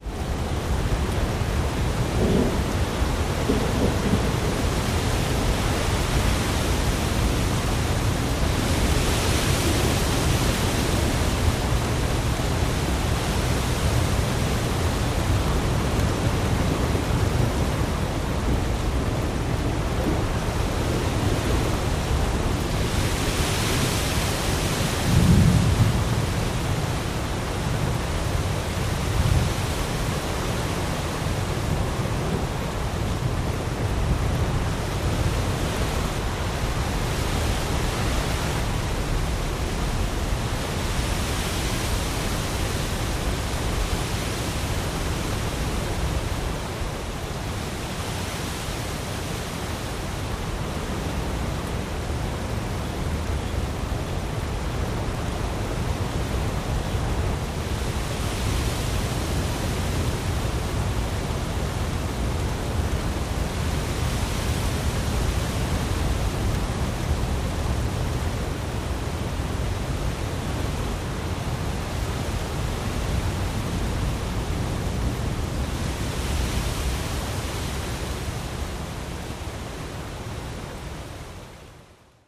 AFX_THUNDERSTORM_DFMG
Thunderstorm